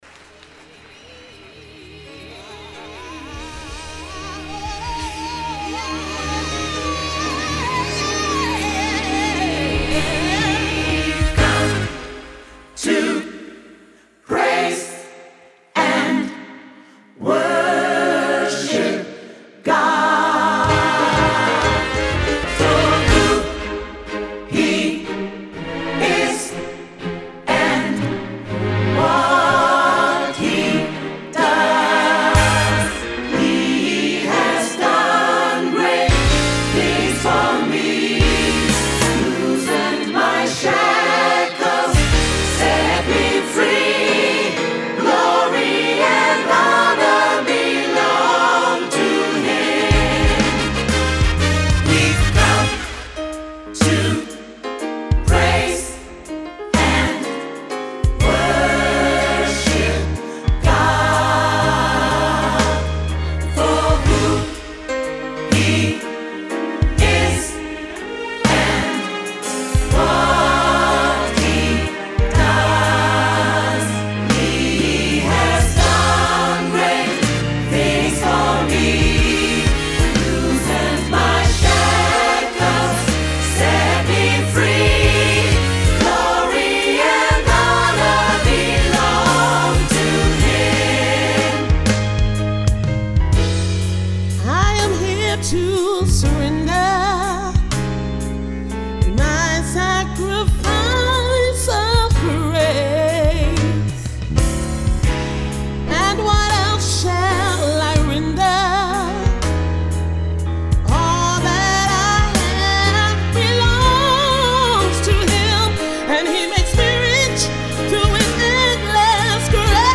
Wirkungsvoller 3/4 Gospel Song
SAB, Solist + Piano